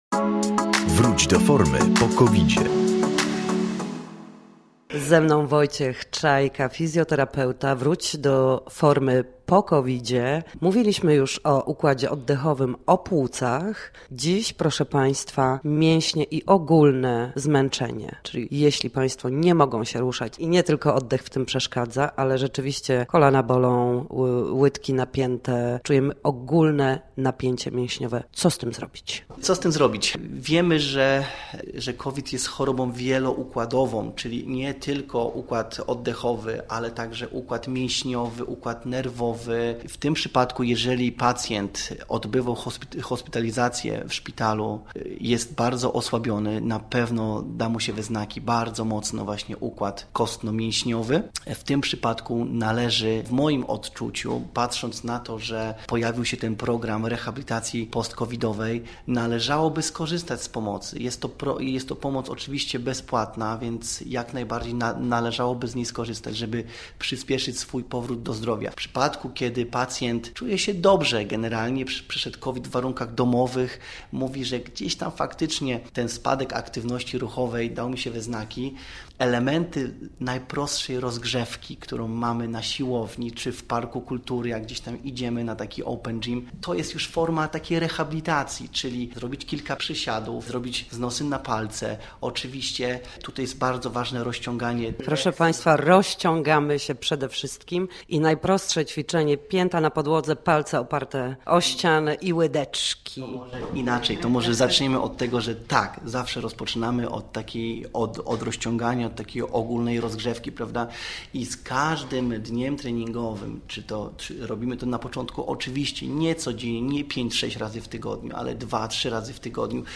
W każdy poniedziałek o godzinie 7:20 na antenie Studia Słupsk przybliżamy Państwu sposoby na